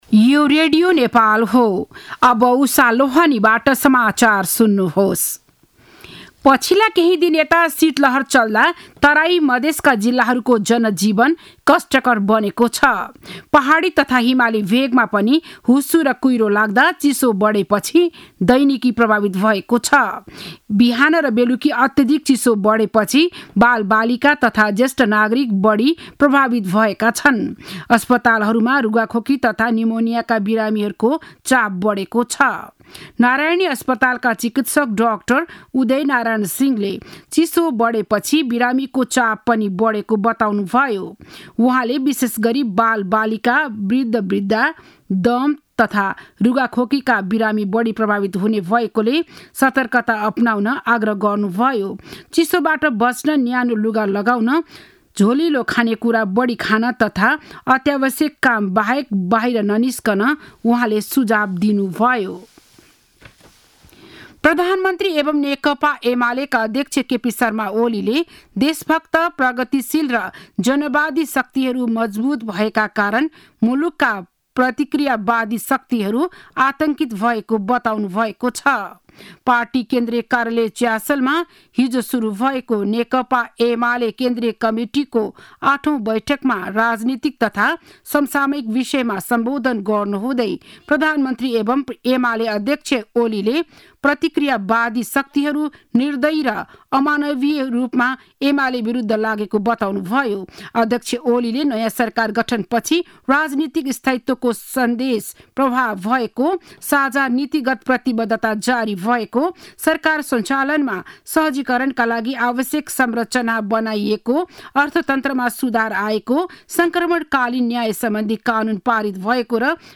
बिहान ११ बजेको नेपाली समाचार : २३ पुष , २०८१
11-am-news-.mp3